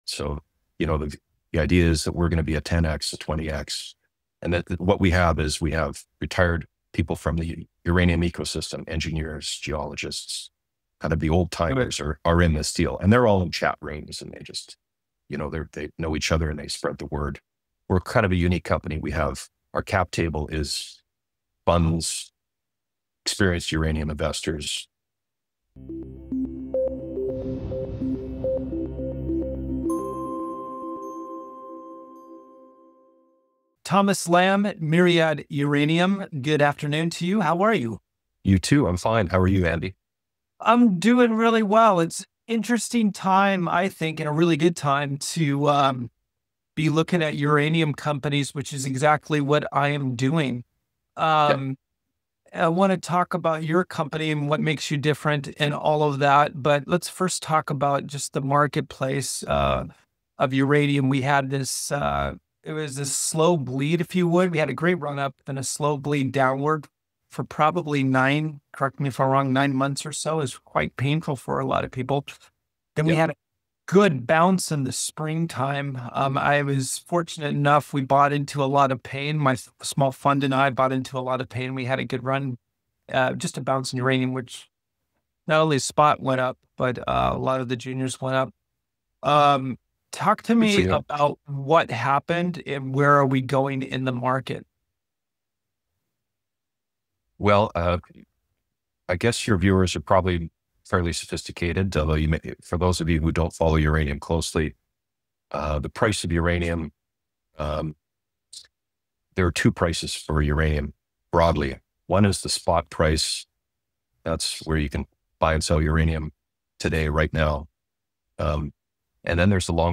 An In Depth Interview